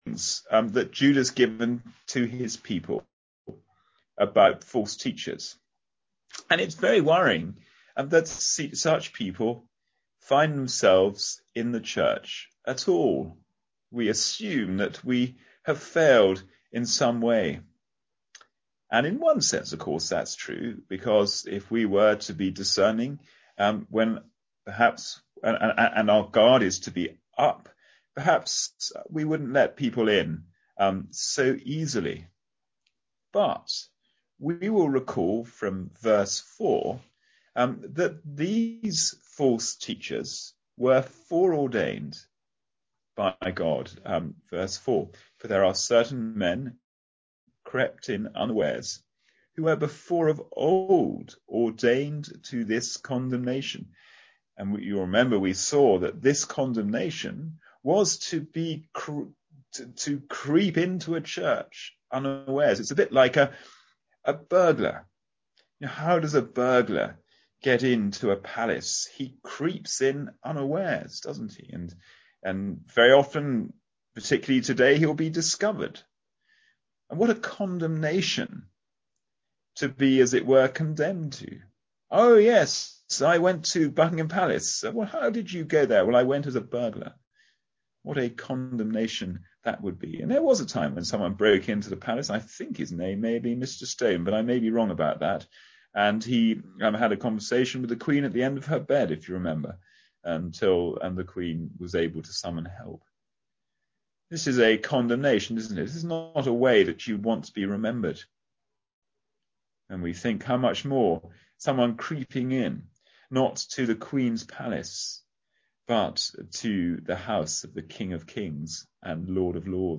Passage: Jude 1:17-25 Service Type: Sunday Morning Service